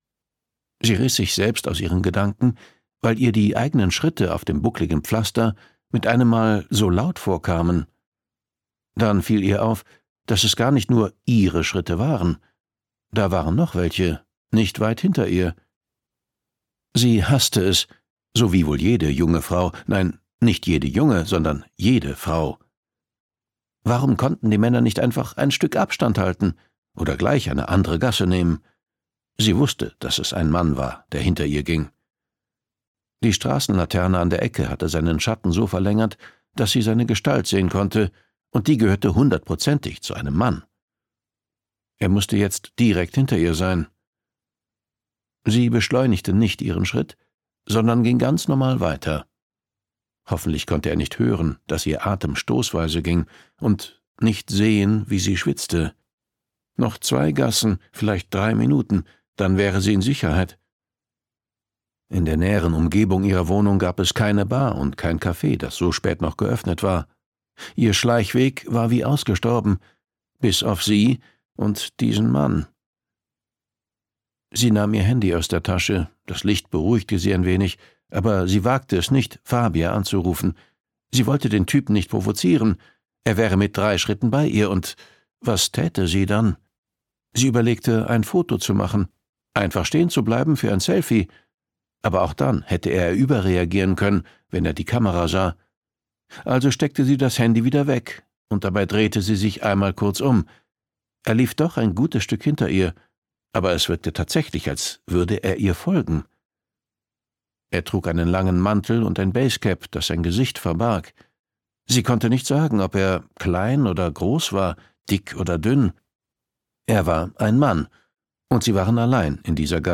Signora Commissaria und die kalte Rache - Alexander Oetker | argon hörbuch
Gekürzt Autorisierte, d.h. von Autor:innen und / oder Verlagen freigegebene, bearbeitete Fassung.